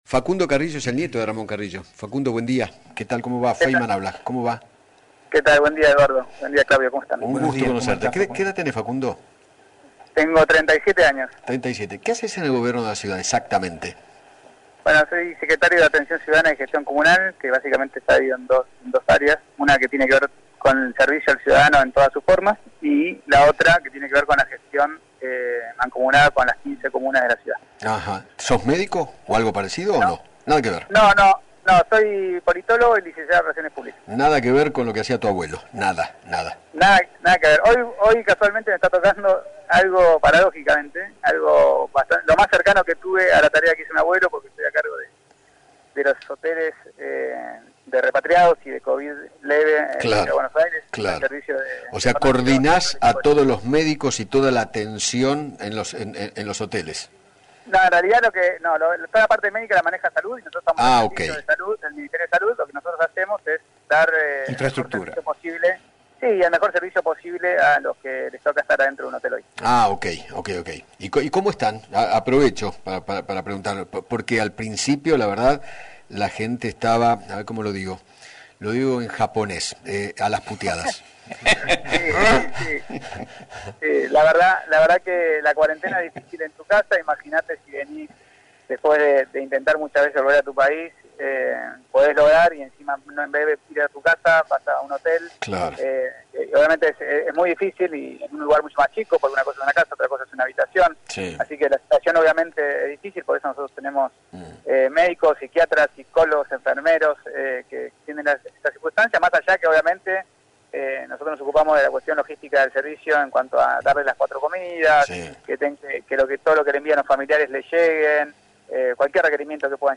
Facundo Carrillo, Secretario de Atención Ciudadana y Gestión Comunal, y nieto de Ramón Castillo, dialogó con Eduardo Feinmann sobre la polémica acusación del Centro Wiesenthal al ex ministro de Salud de Juan Domingo Perón,  a quien consideró “simpatizante de la ideología nazi”, luego de que trascendiera la elección de la cara de Ramón para el posible billete de 5 mil pesos.